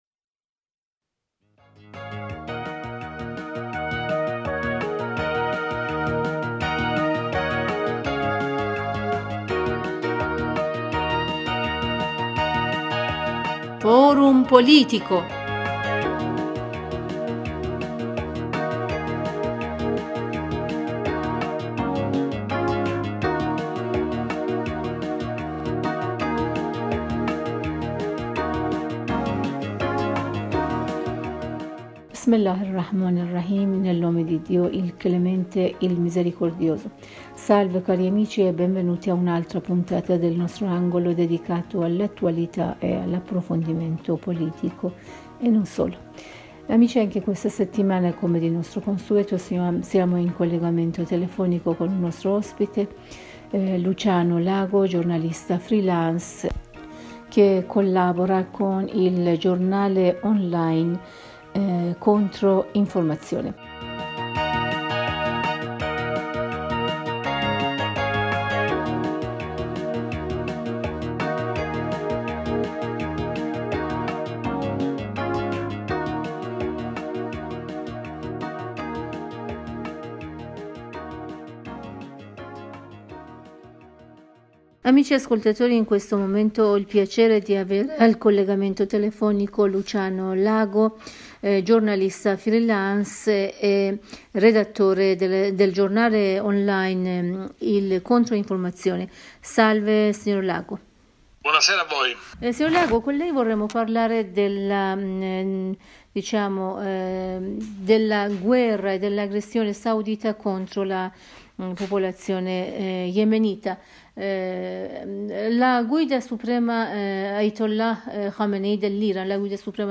Per ascoltare la versione integrale dell'intervista cliccare qui sopra: